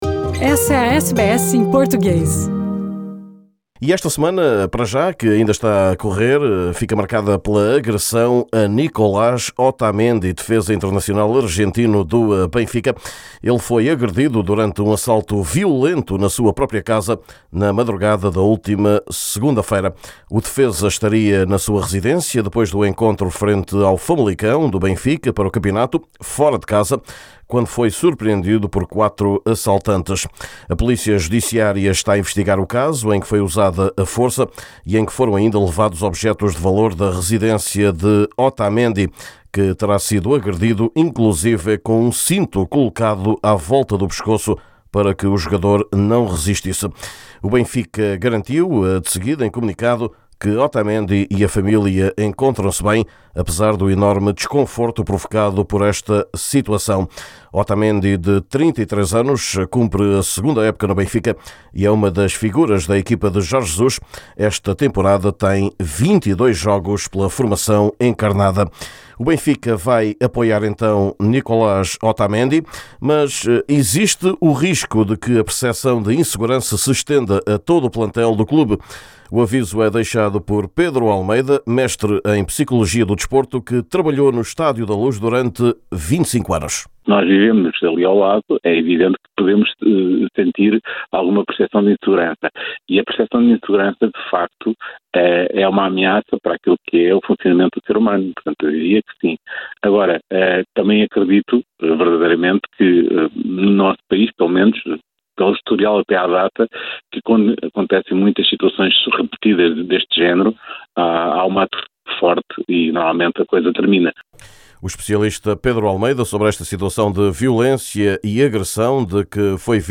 O defesa-central argentino tinha regressado de um jogo da equipa encarnada, fora de casa, quando foi assaltado e agredido em casa. Um psicólogo do desporto fala das implicações de um episódio assim.